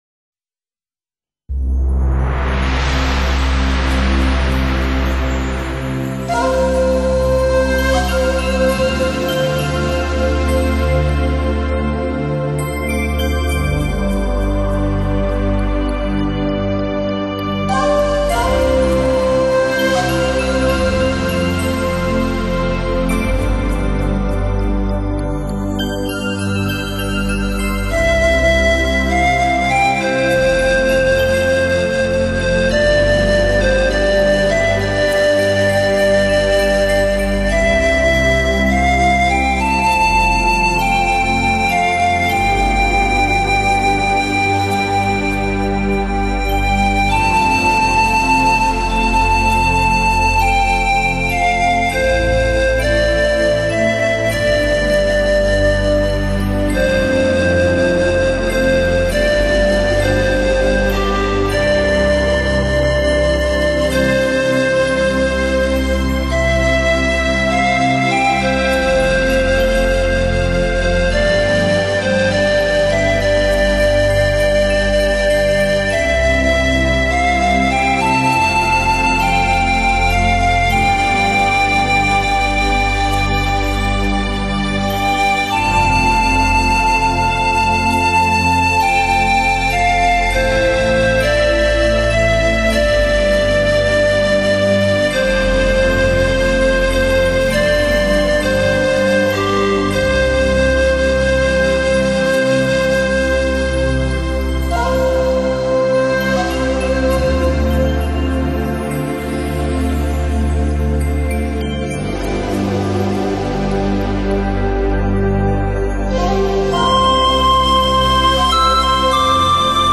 Genre ...........: Meditative
让我们一起放松心情，享受优美音乐带来的浪漫温馨……